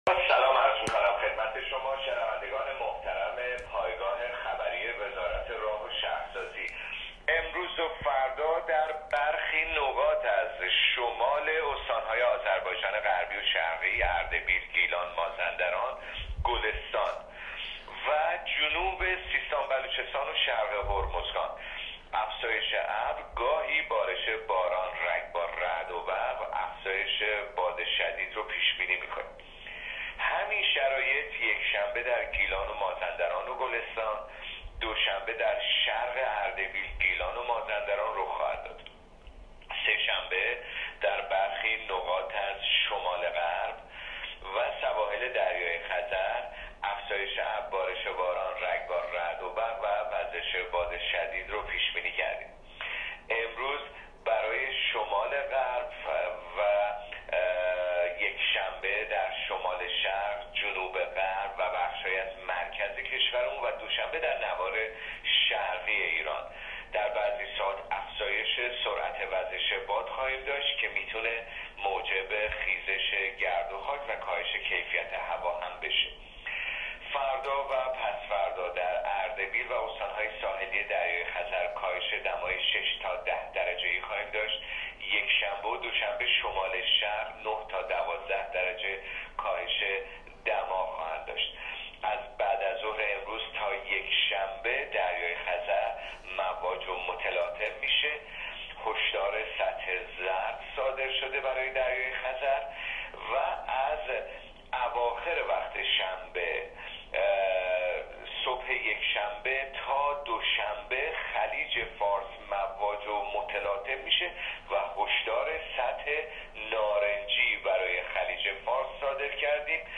کارشناس سازمان هواشناسی کشور در گفت‌وگو با رادیو اینترنتی وزارت راه و شهرسازی، آخرین وضعیت آب‌و‌هوای کشور را تشریح کرد.
گزارش رادیو اینترنتی از آخرین وضعیت آب‌‌و‌‌‌هوای دوم مهر: